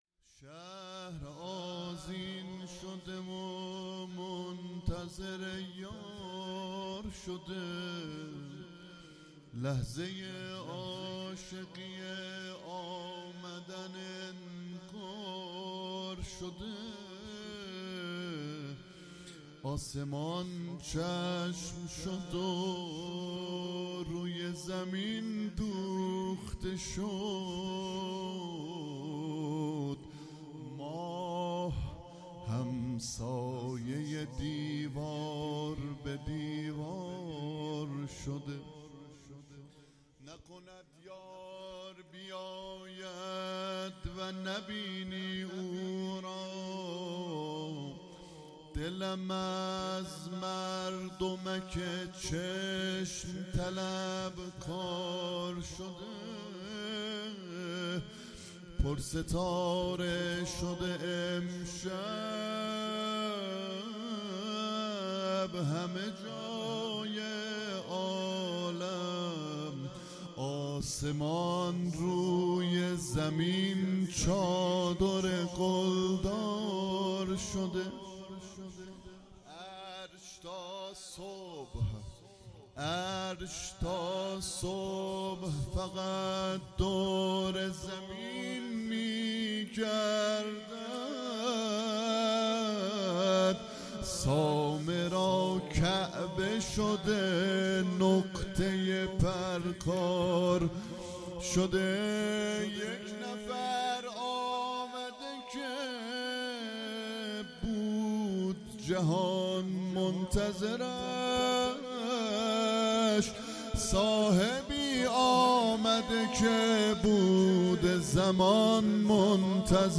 جشن ولادت امام زمان نیمه شعبان 1446